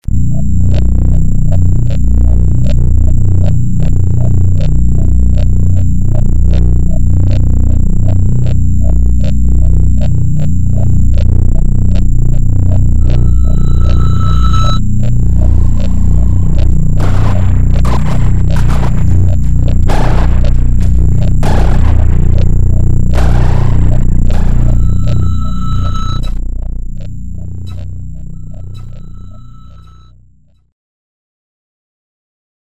tributo in versione sperimentale